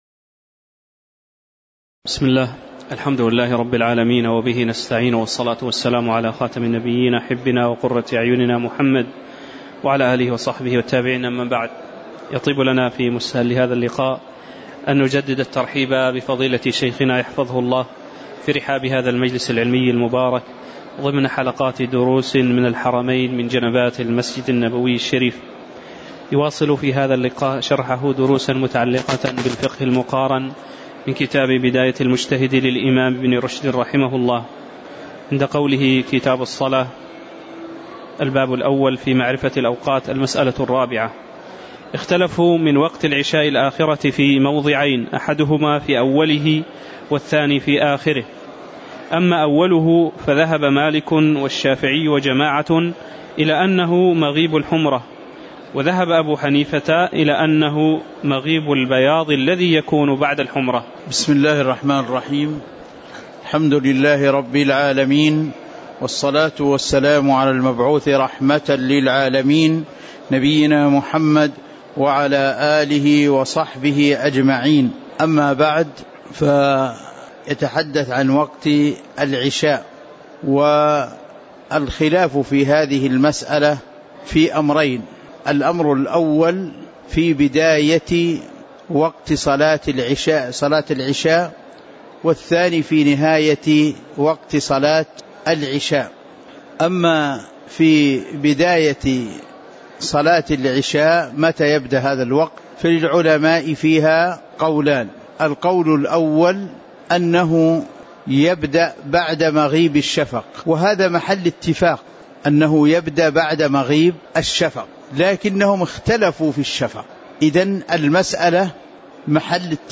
تاريخ النشر ٧ صفر ١٤٤١ هـ المكان: المسجد النبوي الشيخ